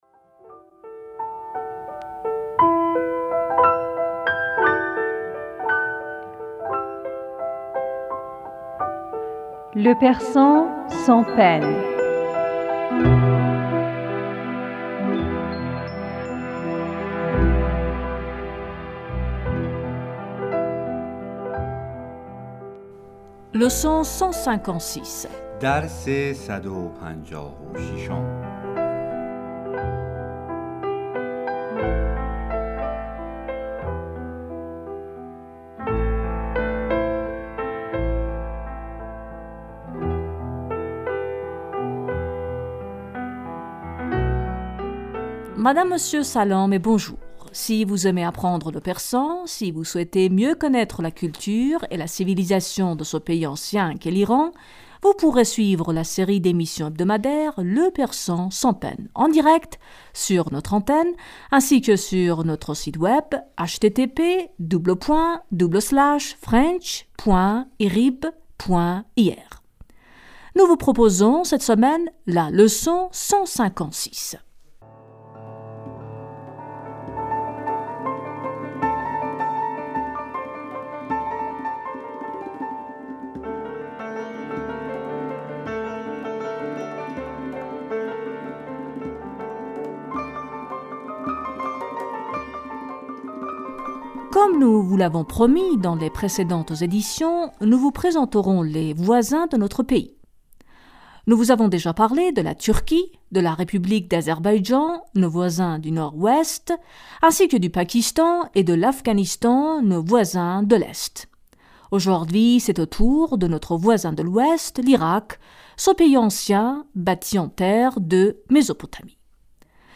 nos cours de persan